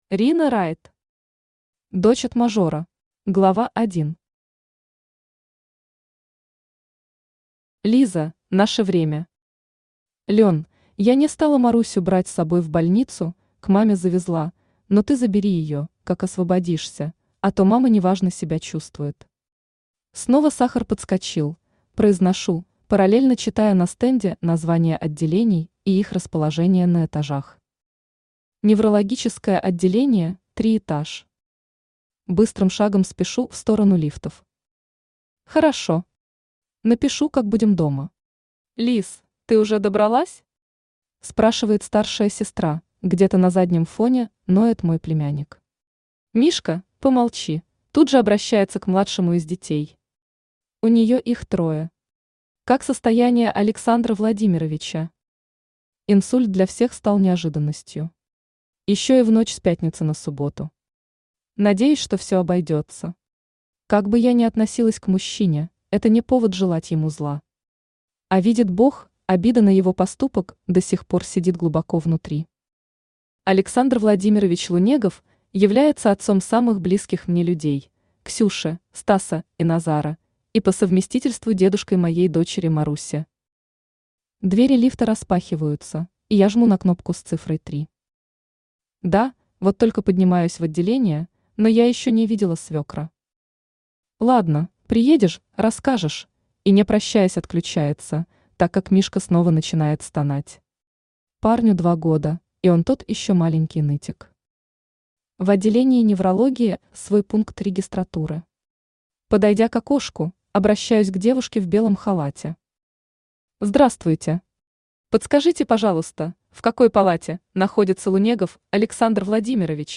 Аудиокнига Дочь от мажора | Библиотека аудиокниг
Aудиокнига Дочь от мажора Автор Рина Райт Читает аудиокнигу Авточтец ЛитРес.